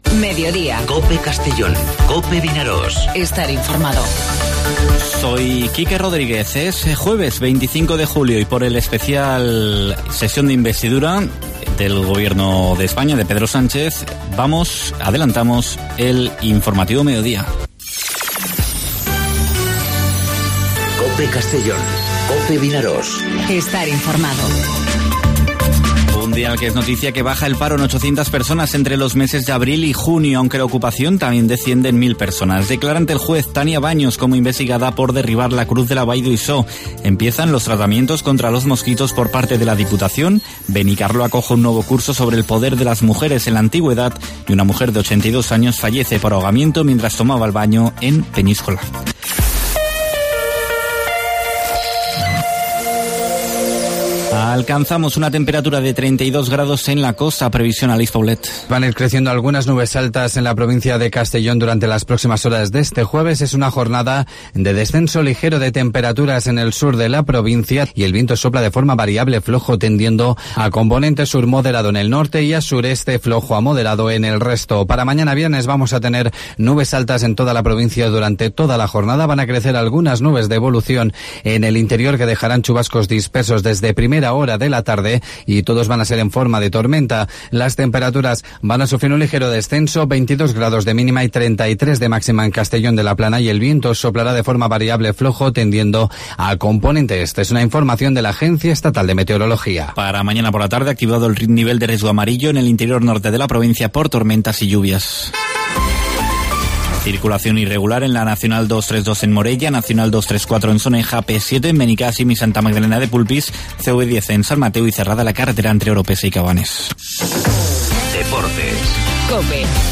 Informativo Mediodía COPE en Castellón (25/07/2019)